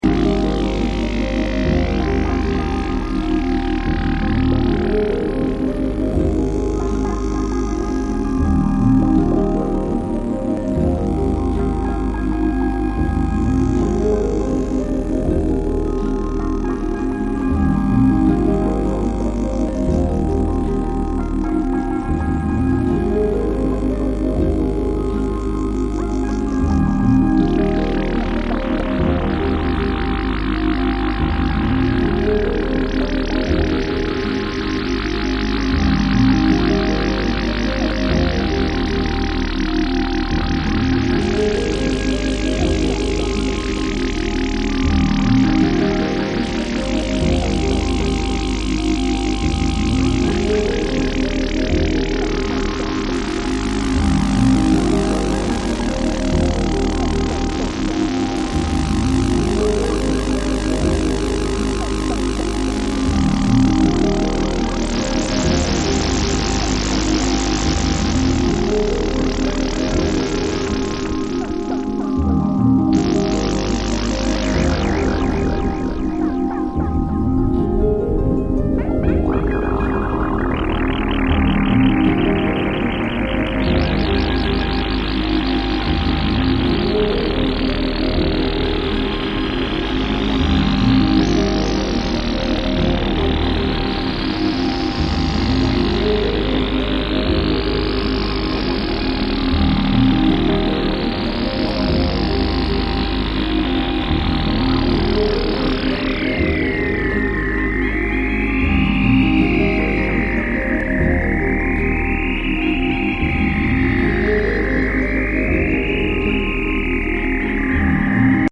grainy leftfield delights
otherworldly tones